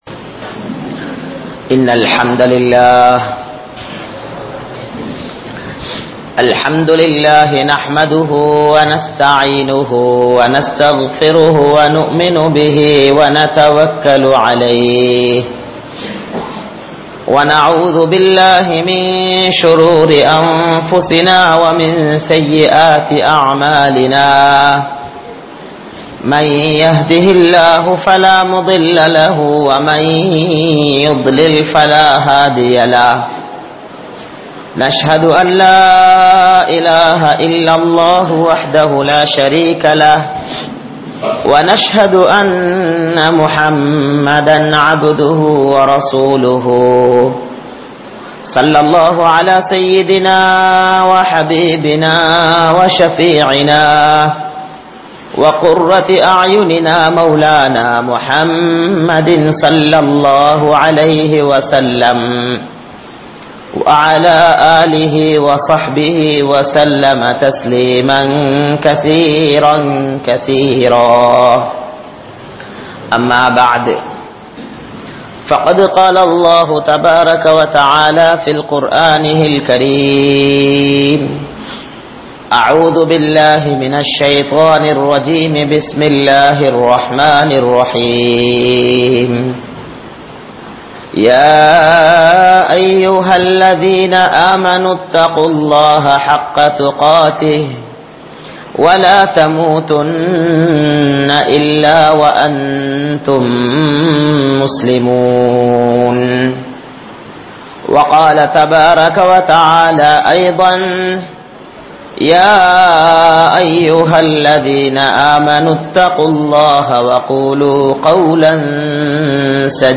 Iruthi Alaippukku Neengal Thayaaraa? (இறுதி அழைப்புக்கு நீங்கள் தயாரா?) | Audio Bayans | All Ceylon Muslim Youth Community | Addalaichenai